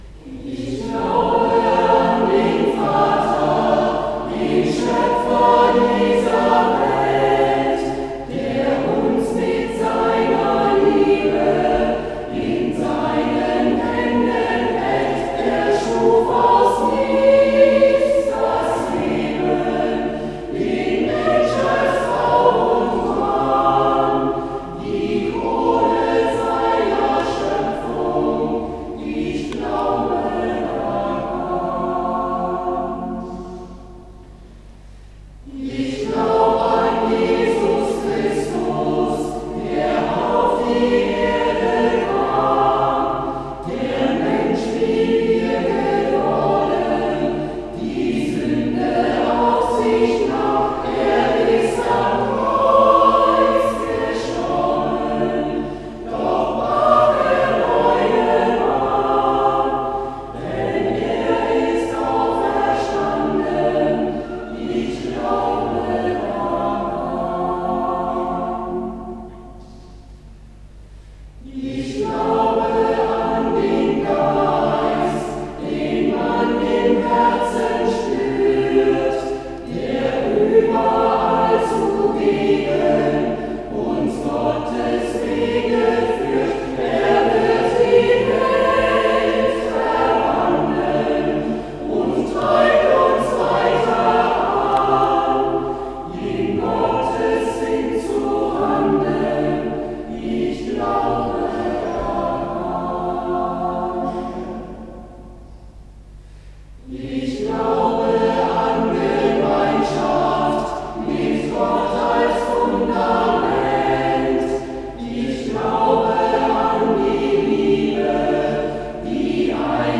Einen krönenden Abschluss fand die Hauptoktav am Sonntag, 13. September 2009 in St. Martinus zu Aldenhoven.
Orgel
Querflöte